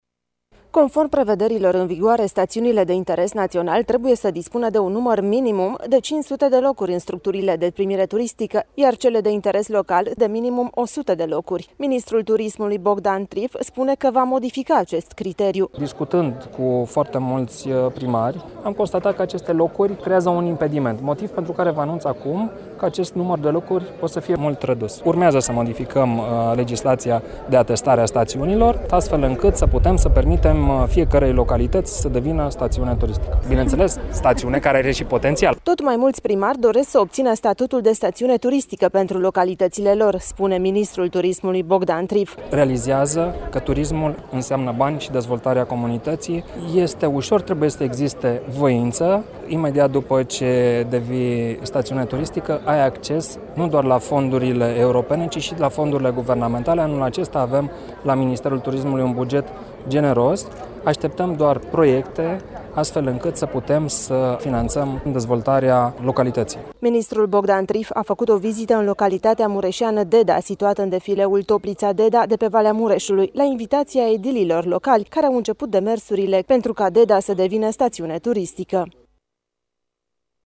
Ministerul Tursimului va propune modificarea legislației privind atestarea stațiunilor pentru a facilita mai multor localități posibilitatea de a obține statutul de stațiune turistică, a declarat astăzi la Deda, în județul Mureș, ministrul Turismului, Bogdan Trif.